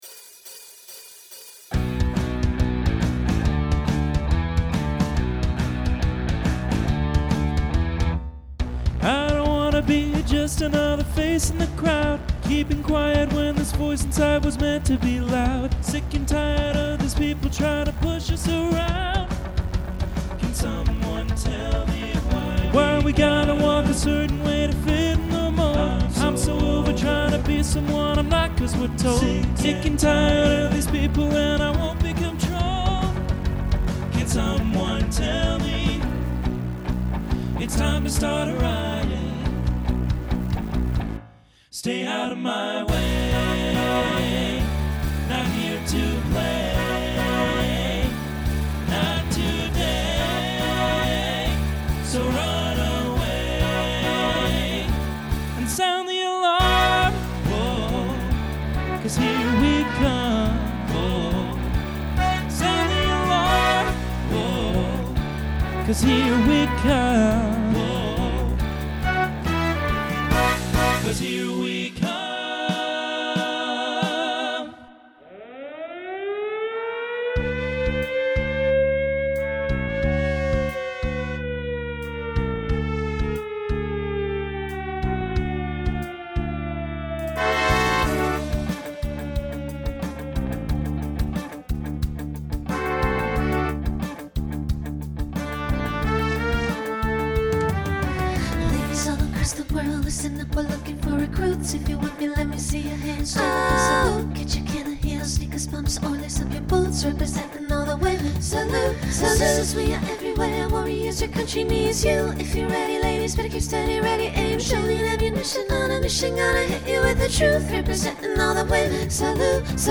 Pop/Dance , Rock
Transition Voicing Mixed